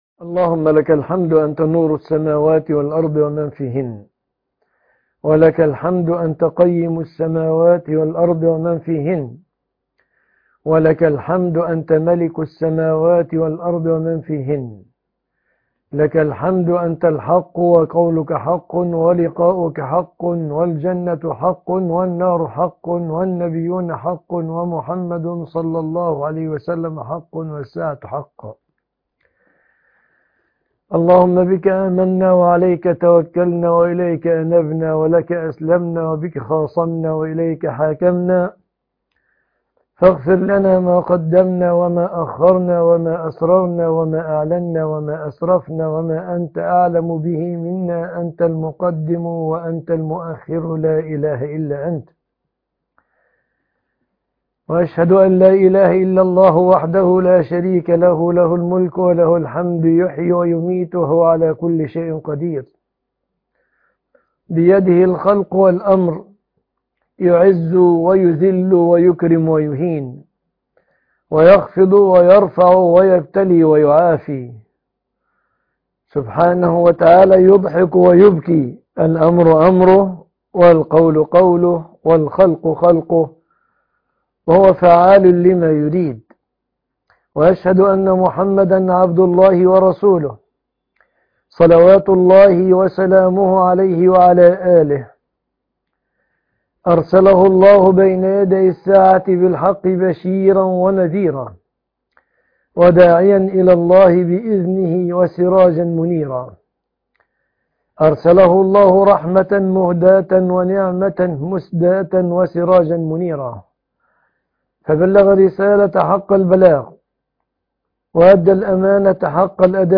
شرط قبول العمل الصالح - خطب الجمعة - فضيلة الشيخ مصطفى العدوي